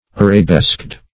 \Ar`a*besqued"\